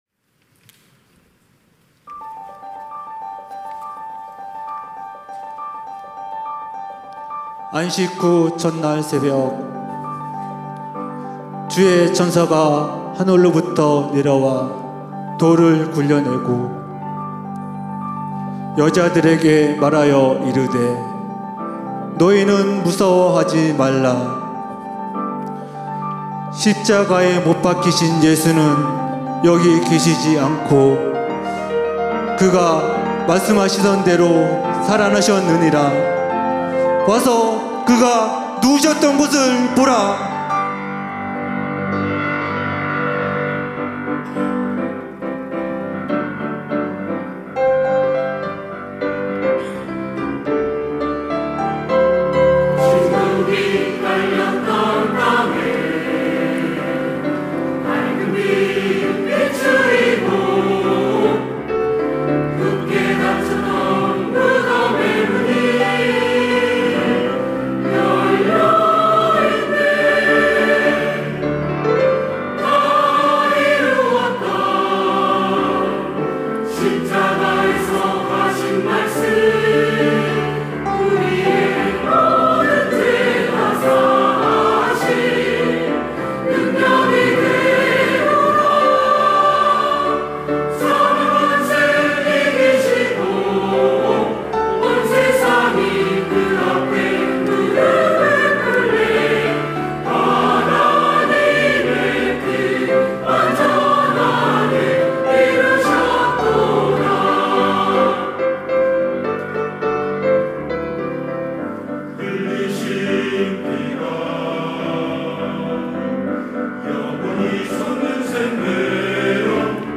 시온(주일1부) - 주 다시 사셨다
찬양대